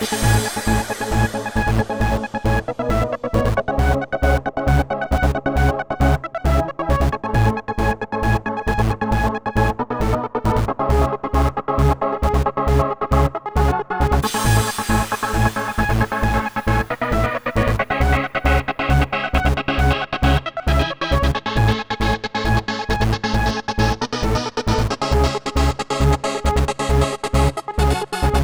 TI CK7 135 Music Full.wav